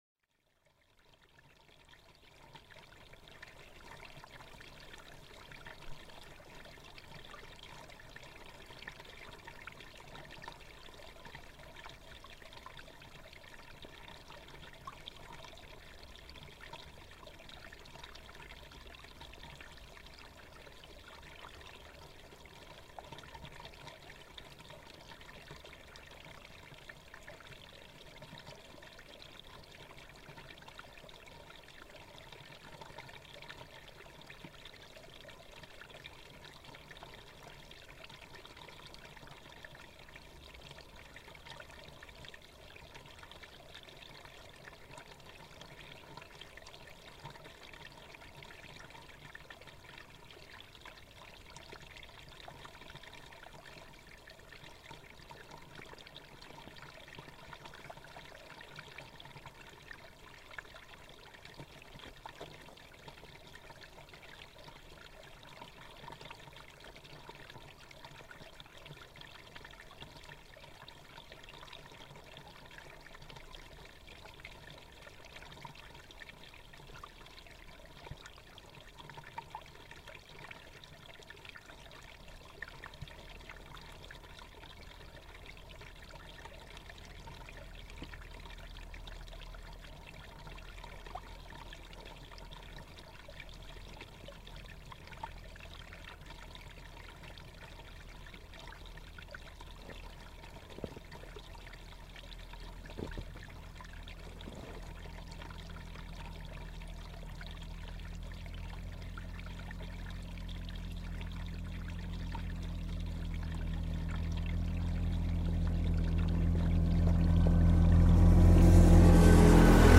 Mountain stream and snowcat, Davos
Facebook Twitter Headliner Embed Embed Code See more options A recording of a peaceful mountain stream in the Alps above the town of Davos is interrupted by a passing snowcat tending to the pistes. Recorded during the World Economic Forum, when the town below was full of the world's most influential business and political leaders - but above the town, all is peaceful and normal.